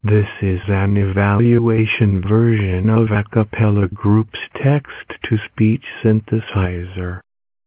Voices list
Arabic (Saudi Arabia) Leila   HQ   F pdf      leila22k